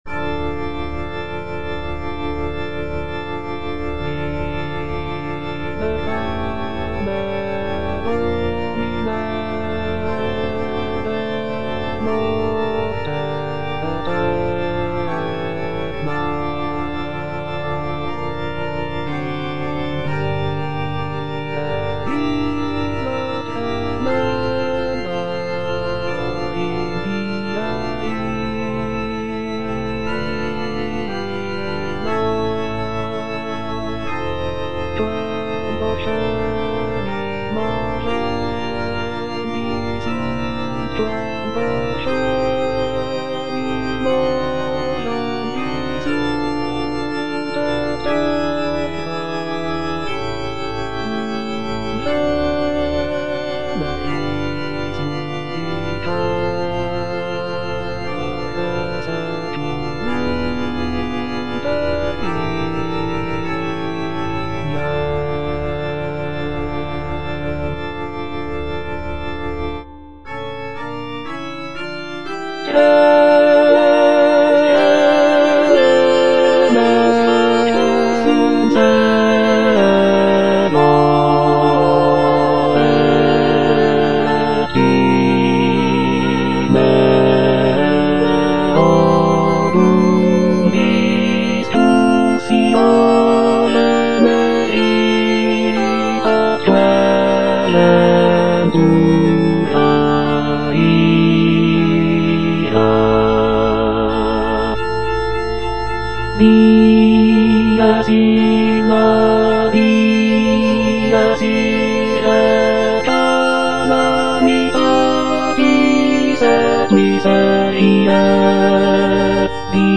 G. FAURÉ - REQUIEM OP.48 (VERSION WITH A SMALLER ORCHESTRA) Libera me (bass II) (Emphasised voice and other voices) Ads stop: